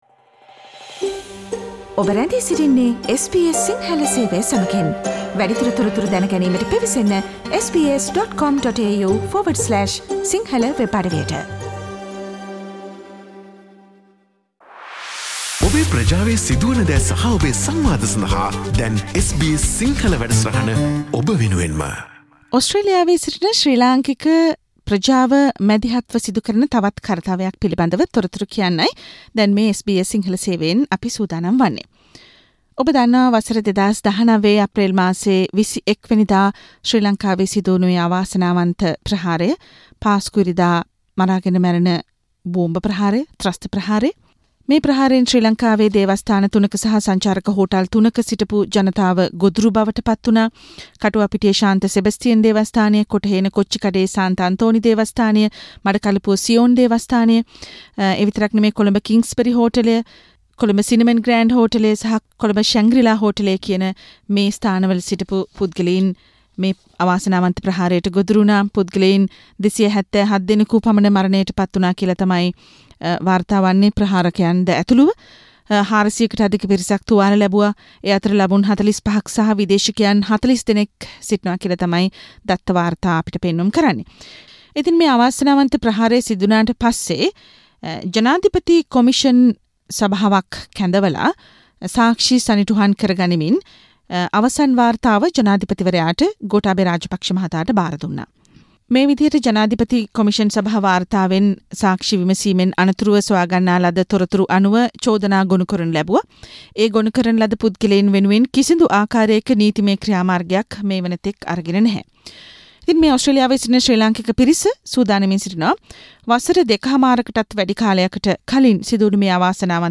ශ්‍රී ලංකාවේ සිදුවූ පාස්කු ප්‍රහාරයට සම්බන්ද වගකිවයුත්තන් නීතිය ඉදිරියට පමුණවා වින්දිතයින්ට යුක්තිය හා සාධාරණය ඉටුකරගැනීමට මැදිහත් වන ලෙසට ඕස්ට්‍රේලියානු රජයෙන් ඉල්ලා සිටීම පෙරදැරි කොටගත් පෙත්සමක් ඕස්ට්‍රේලියාවේ සිටින ශ්‍රී ලාංකික පිරිසක් විසින් සංවිධානය කිට තිබේ. ඒ පිළිබඳව වන සාකච්ඡාවට සවන්දෙන්න…